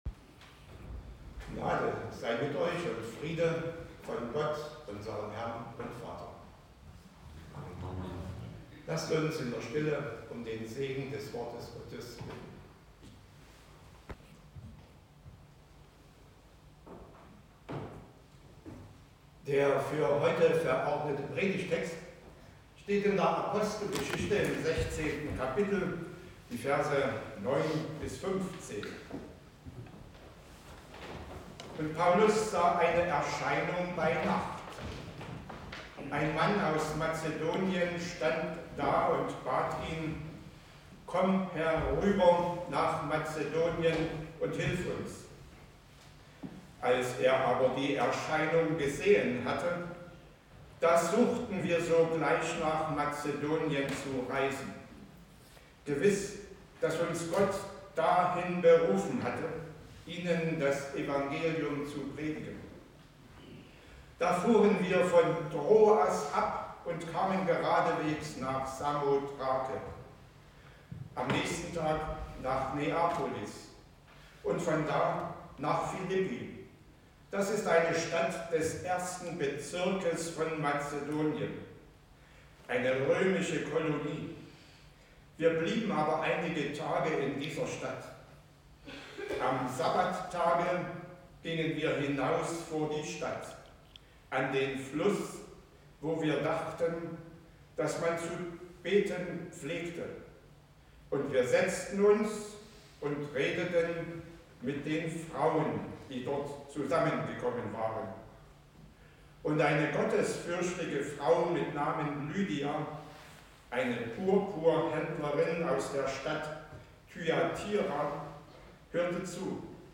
Passage: Apostelgeschichte 16; 9-15 Gottesdienstart: Predigtgottesdienst Wildenau « Selbstbestimmt Leben und Sterben